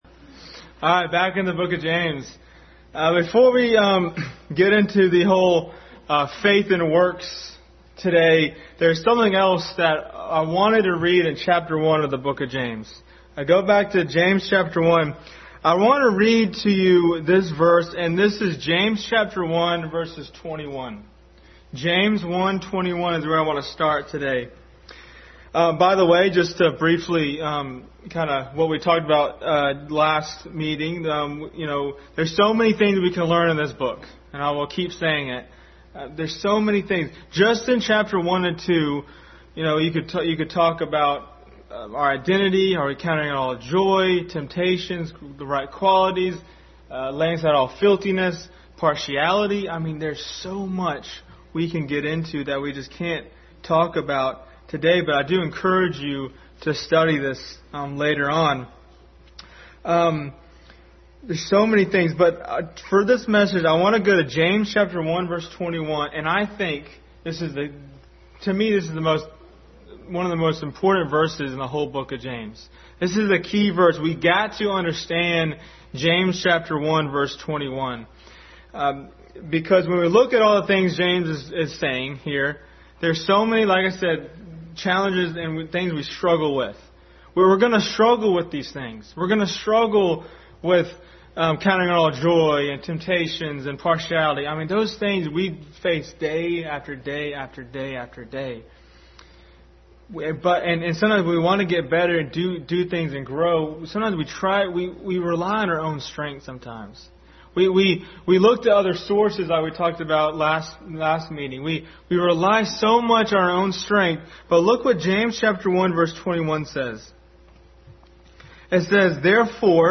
Faith and Works Passage: James 1:21, 2:14-26, Romans 4:1-8, Hebrews 11:31, John 10:27-30 Service Type: Family Bible Hour